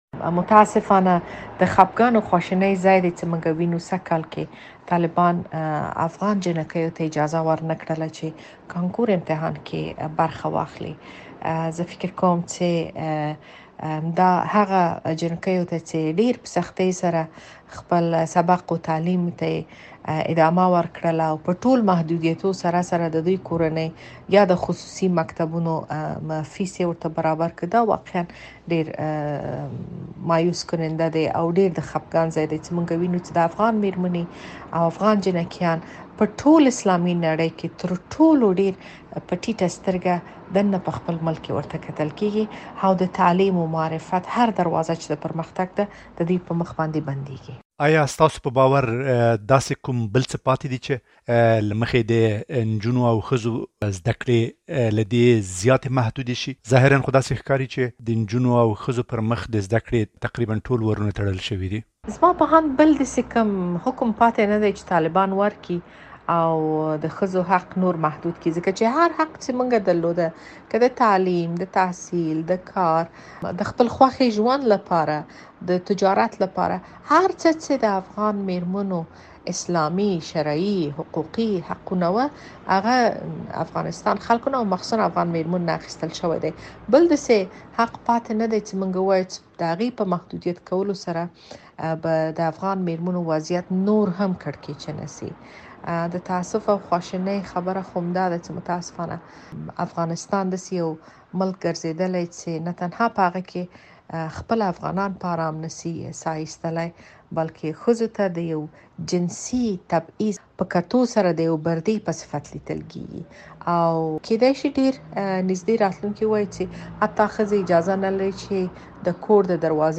د شکريې بارکزۍ مرکه